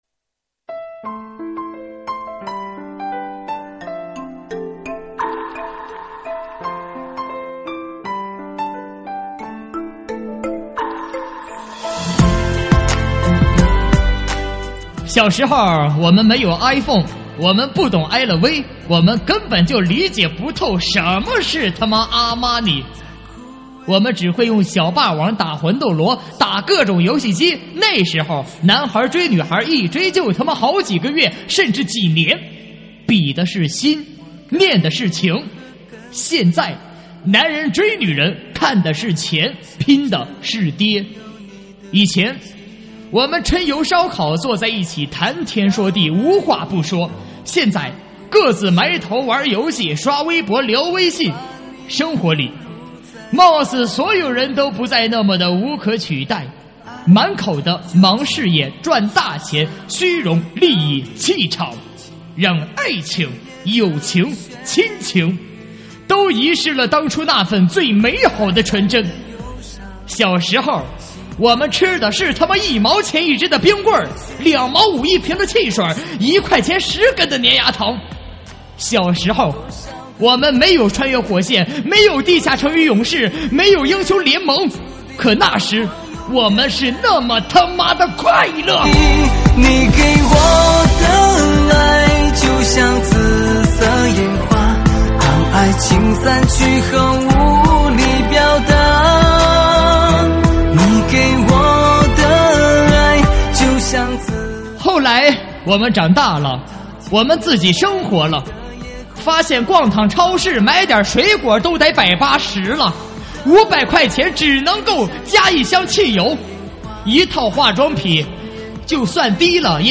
喊麦现场